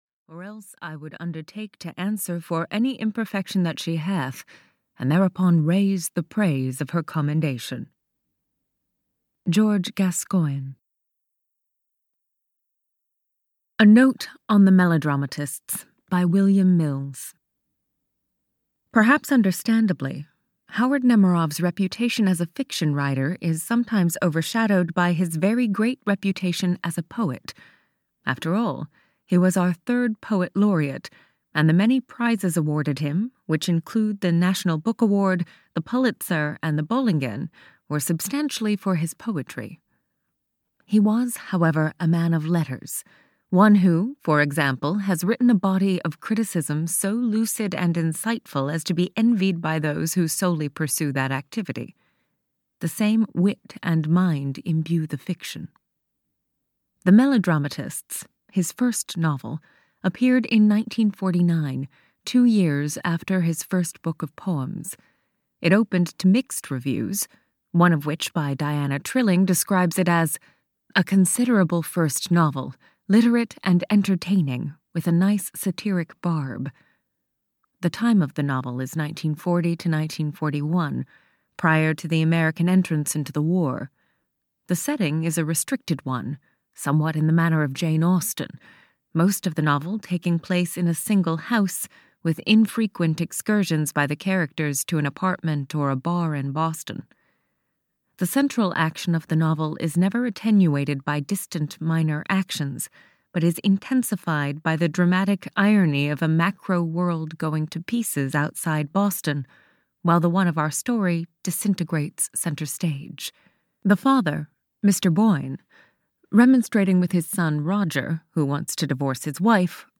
The Melodramatists (EN) audiokniha
Ukázka z knihy